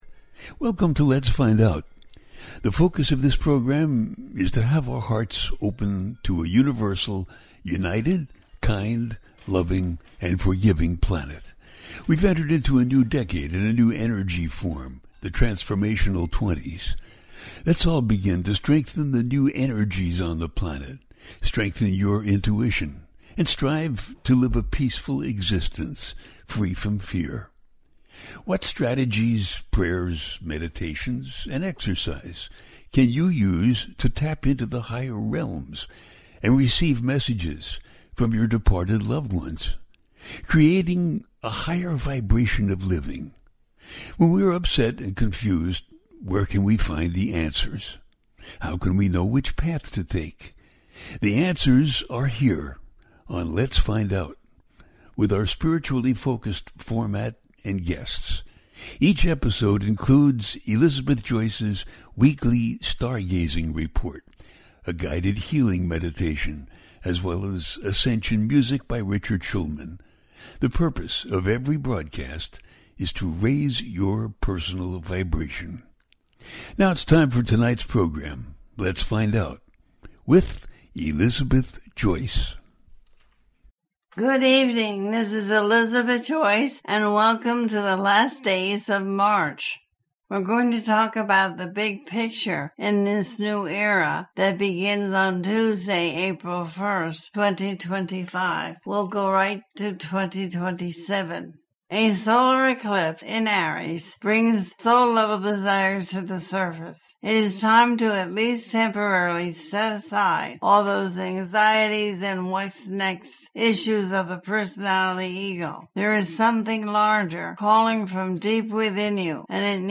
The Big Picture In This New Era - 2025-2027 - A teaching show